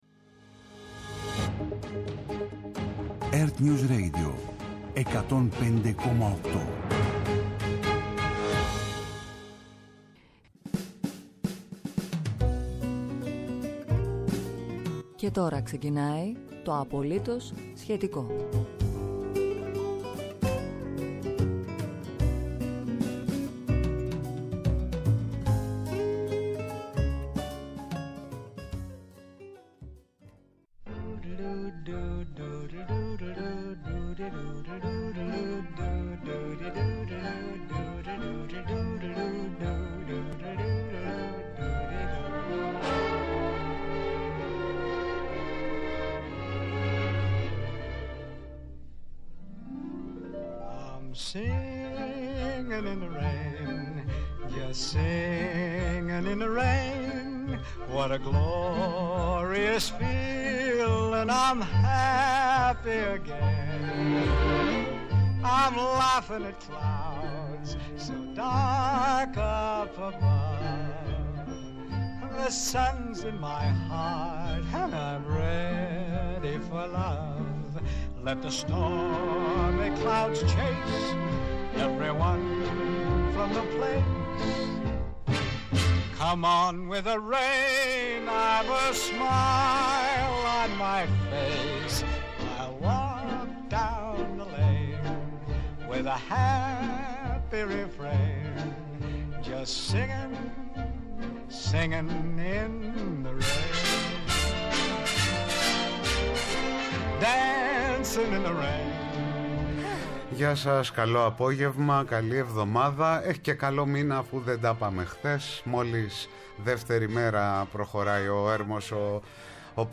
ΕΡΤNEWS RADIO